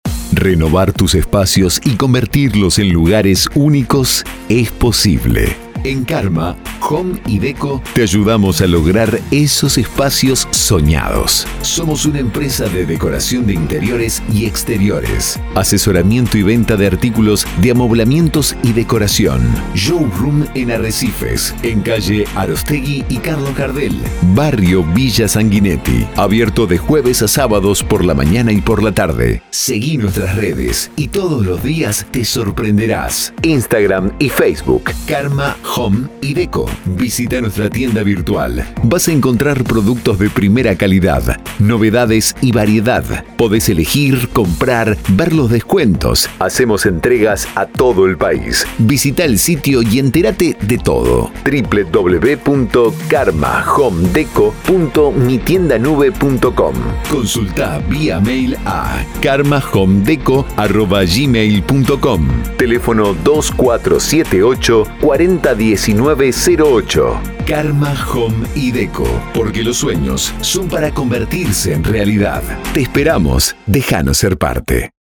Spot comercial con locución institucional .